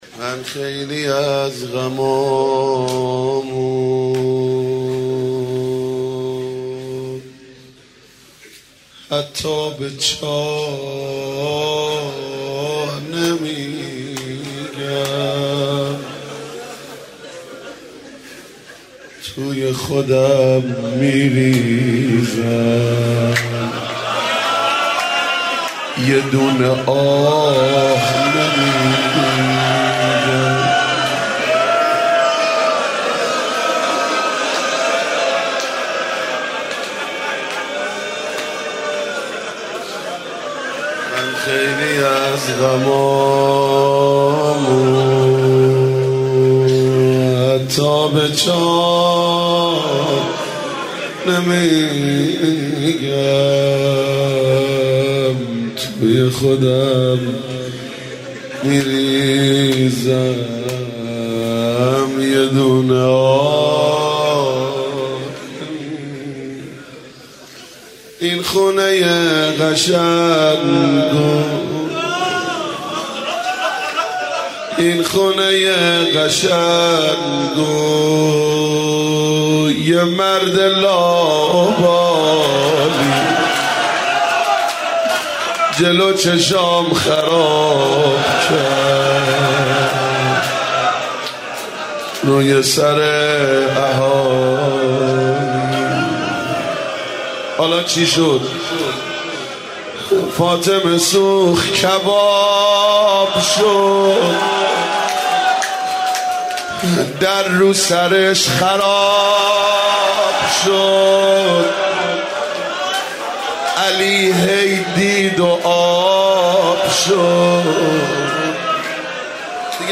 دانلود مداحی غم عالم - دانلود ریمیکس و آهنگ جدید
روضه خوانی در مظلومیت بانوی دوعالم حضرت زهرا(س)